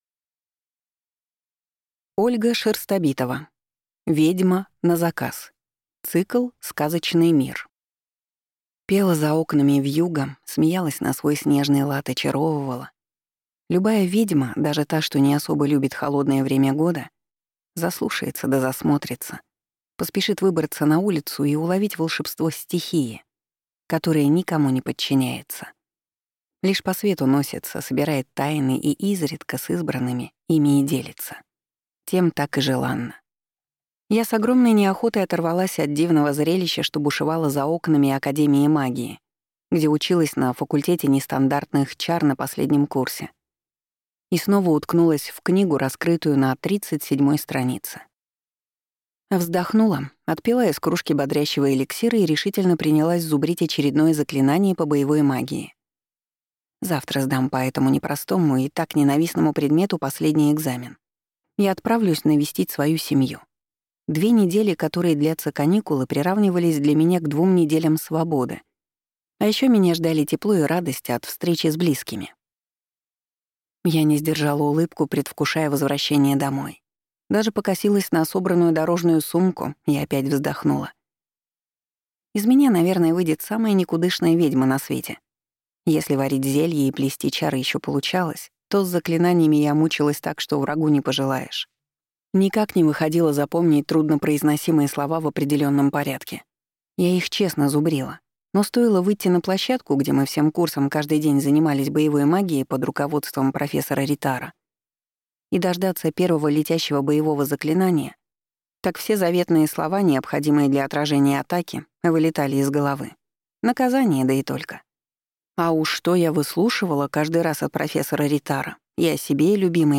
Аудиокнига Ведьма на заказ | Библиотека аудиокниг